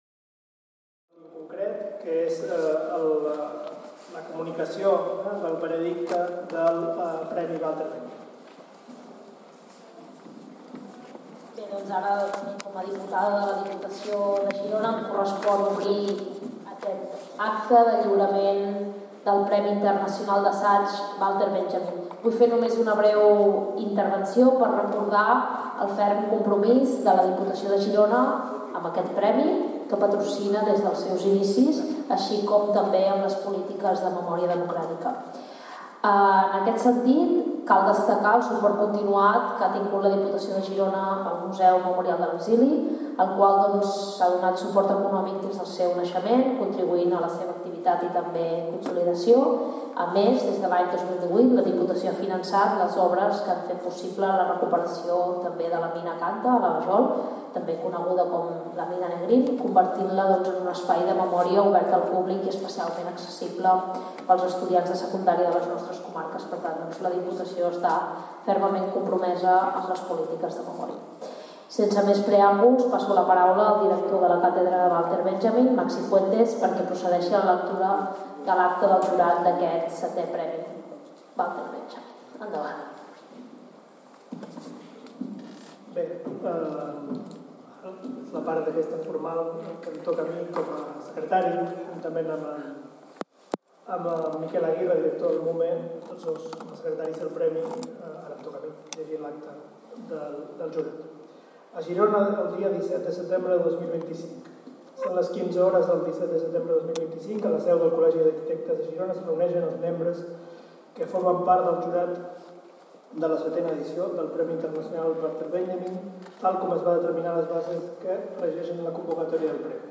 Acte de lliurament del Premi Internacional Walter Benjamin 2025 en el marc del seu 11è Col·loqui Internacional celebrat a Porbou. El premi ha estat concedit a Helen Graham, una de les historiadores de parla anglesa més destacades del moment, especialitzada en la història d’Espanya durant el segle XX